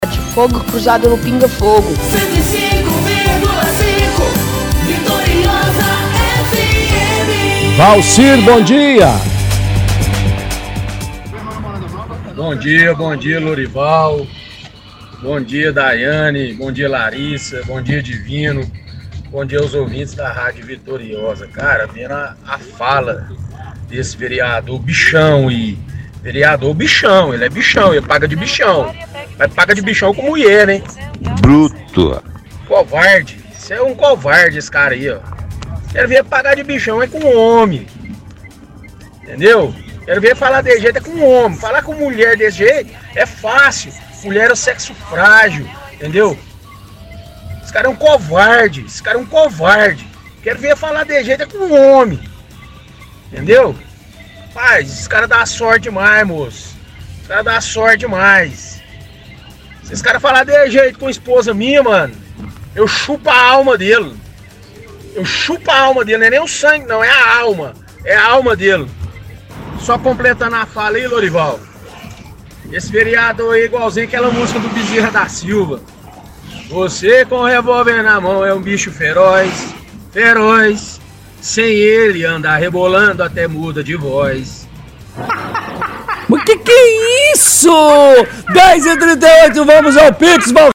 – Ouvinte fala “Vereador paga de bichão, falar com mulher assim, quero vê ele falar assim com homem”